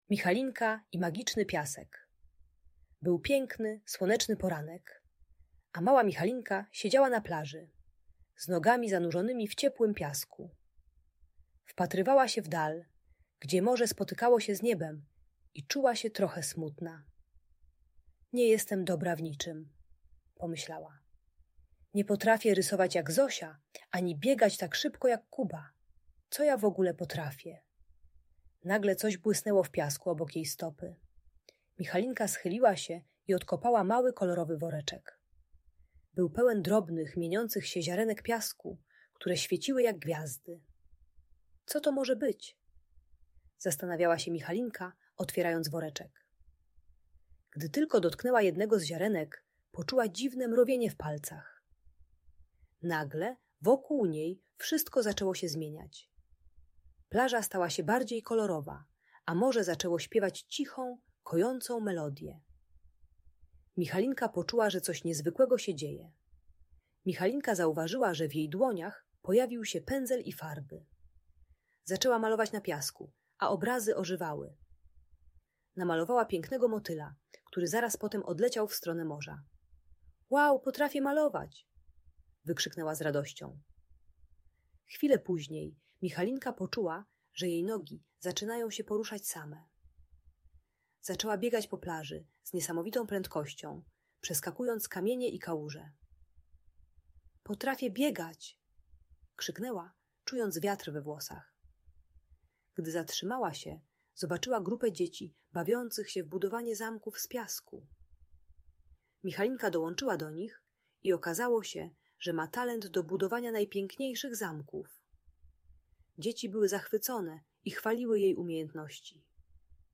Michalinka i magiczny piasek - Niepokojące zachowania | Audiobajka